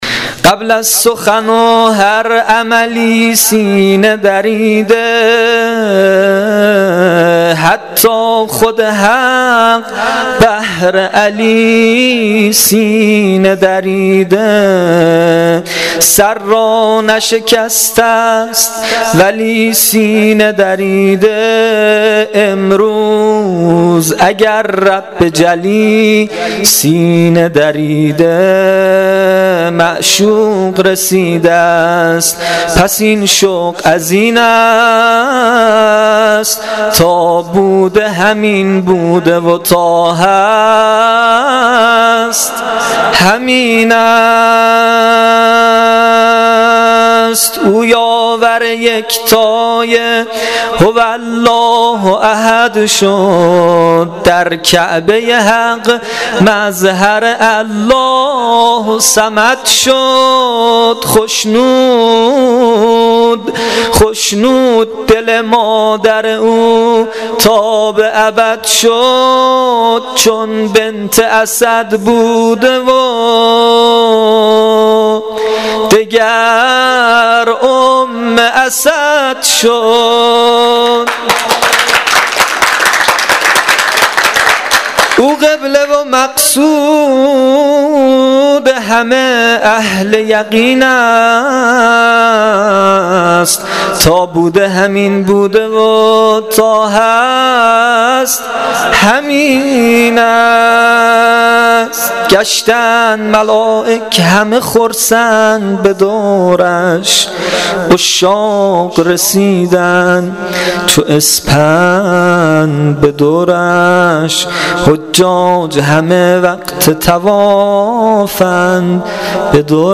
مدح شب میلاد امام علی (علیه السلام)